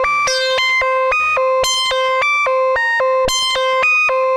Index of /musicradar/80s-heat-samples/110bpm
AM_CopMono_110-C.wav